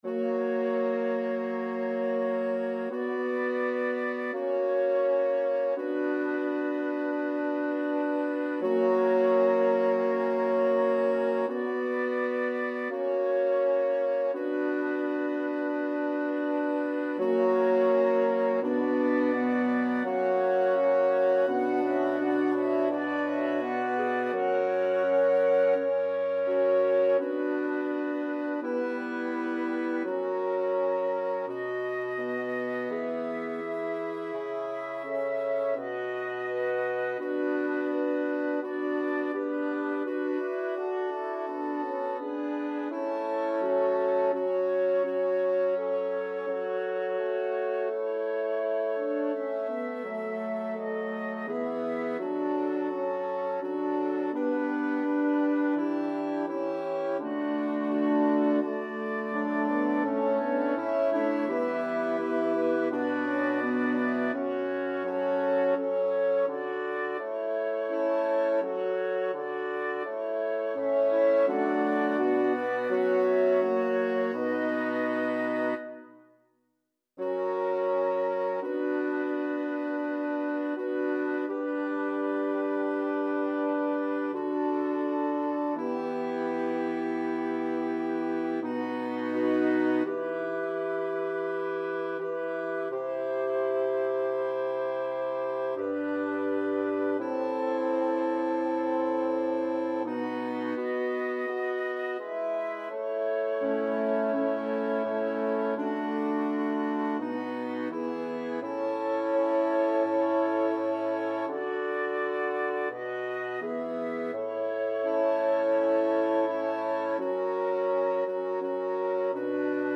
Number of voices: 8vv Voicing: SATB.SATB Genre: Sacred, Motet
Language: Latin Instruments: A cappella
Description: A rearrangement of Palestrina's 6 voice setting for double choir.